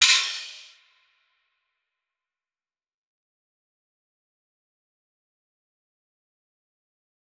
Crashes & Cymbals
DMV3_Crash 6.wav